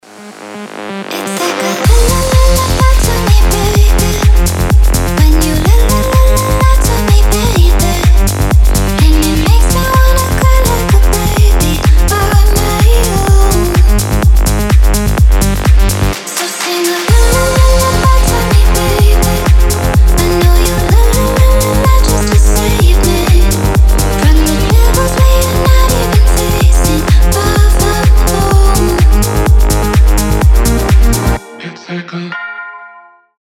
• Качество: 320, Stereo
красивый женский голос
кайфовые
ремиксы